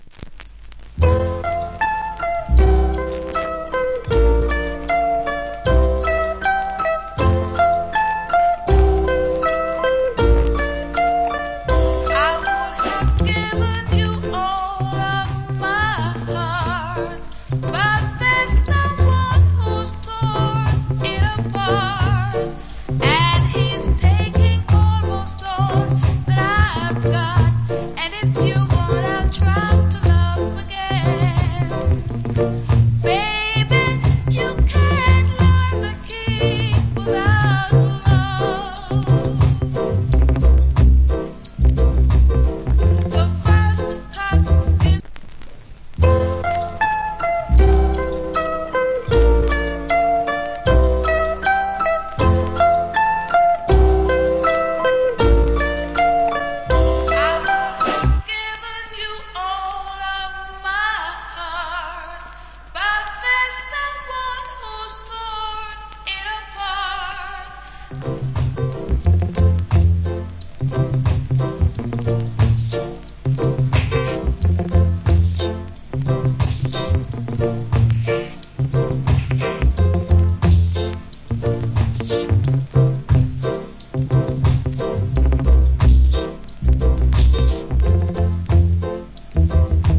Old Hits Rock Steady Vocal.